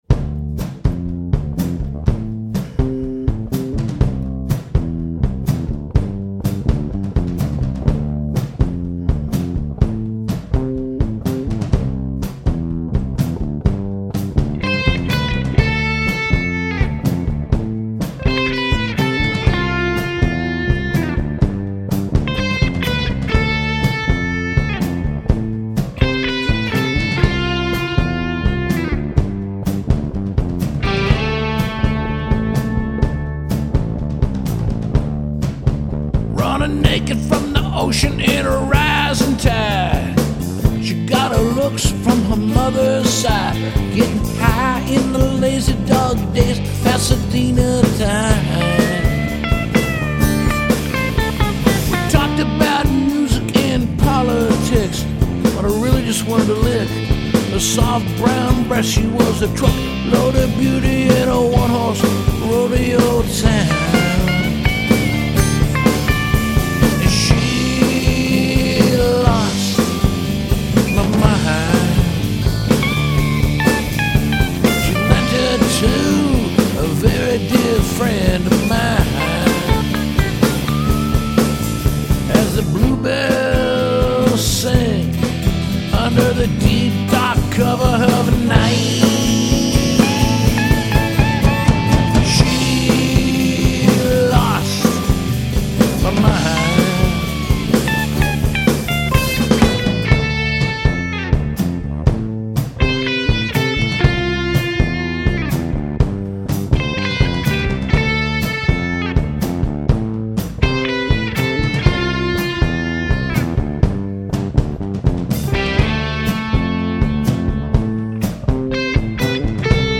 bass playing
drumming